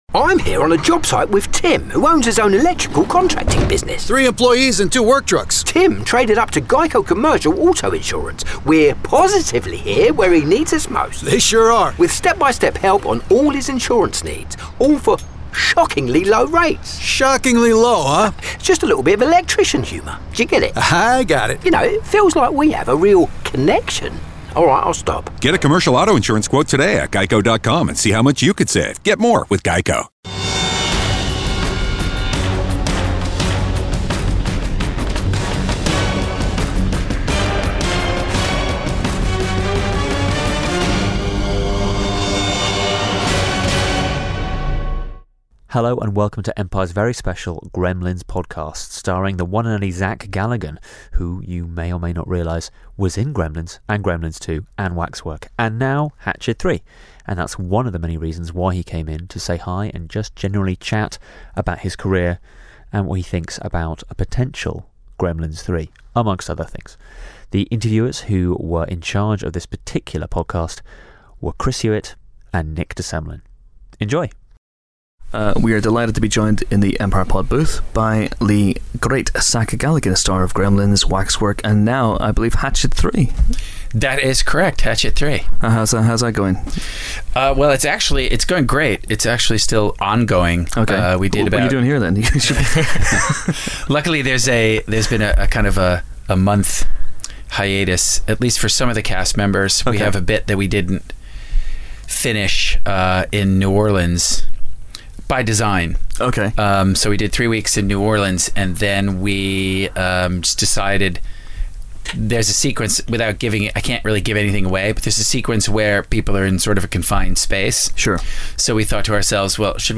Zach Galligan, the star of Gremlins, Gremlins 2: The New Batch, Waxwork and many more stopped by the Empire podbooth for a chat about his career, with the emphasis most definitely on his work with the likes of Gizmo and Mogwai. Here's the result, which even features titbits on a possible Gremlins 3 project...